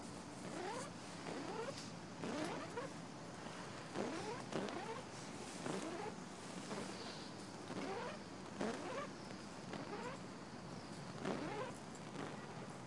焚烧塑料袋Zilch的声音3
描述：几个塑料袋"zilches" 着火的声音。自然界的声音，背景是鸟类。 2月21日清晨，克拉克福克河附近。
Tag: 烧伤 消防 现场录音 噪音 Plasting